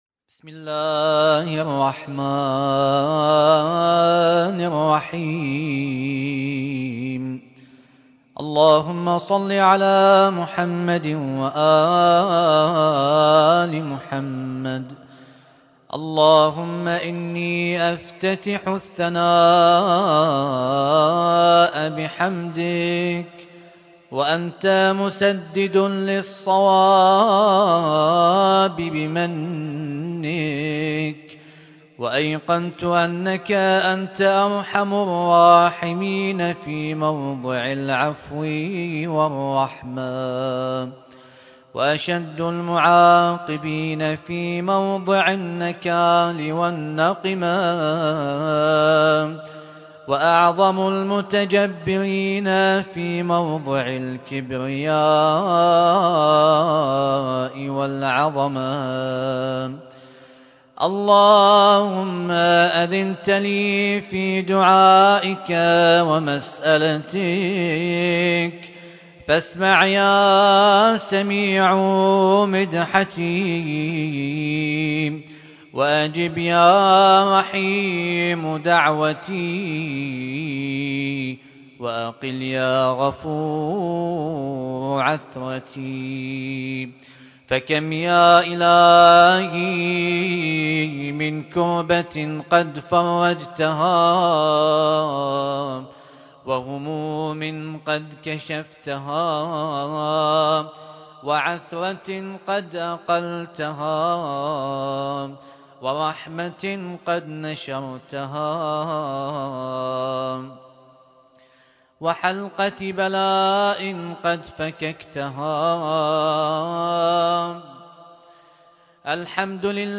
اسم التصنيف: المـكتبة الصــوتيه >> الادعية >> دعاء الافتتاح